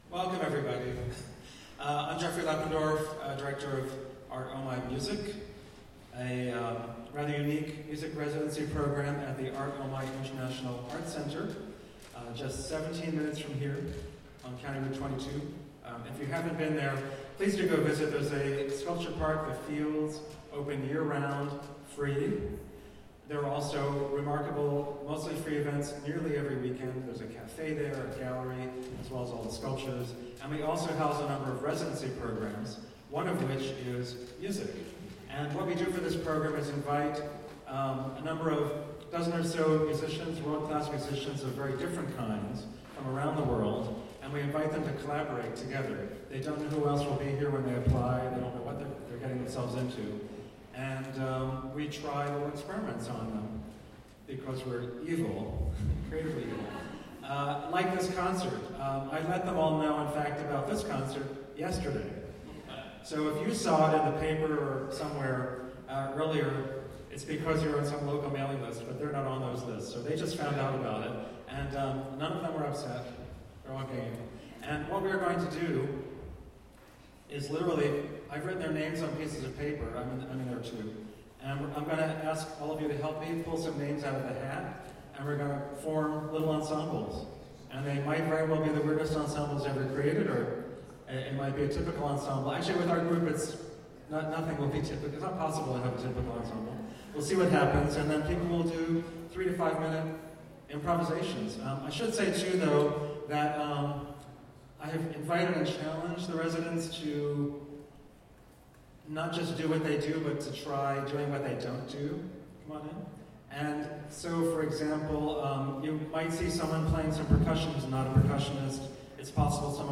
Saturday Morning Serial: Art Omi Music Guerilla Basilica Improvisations (Audio) Aug 19, 2017 broadcasts Art Omi Music Guerilla Basilica Improvisations : Aug 19, 2017: 11am - 12pm Recorded from a live event on August 12, 2017 at B...
Recorded from a live event on August 12, 2017, this world music avant-garde celebration welcomed the 2017 Art Omi: Music international musician fellows, who participated in a Cagean game of "guerrilla improvisations"--spontaneously created ensembles picked from a hat by the audience.